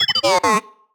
sci-fi_driod_robot_emote_neg_05.wav